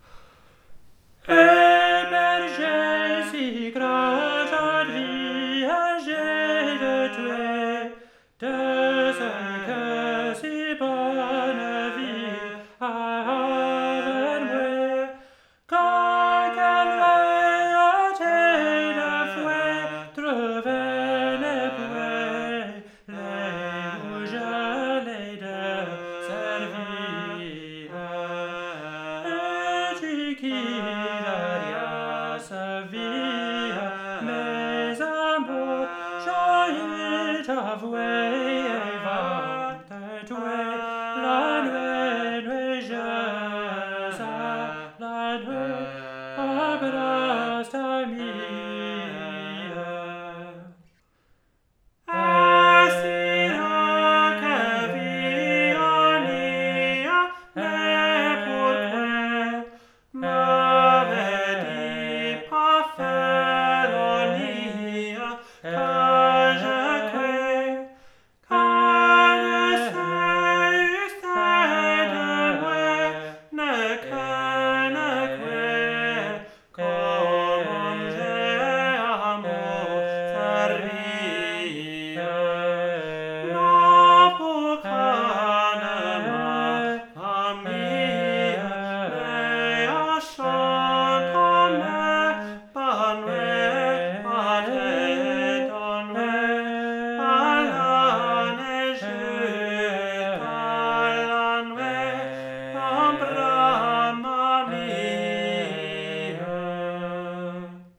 2. A version of the song sung as a motet with the motet repeated for the second stanza.
he-bergiers-motet-1.wav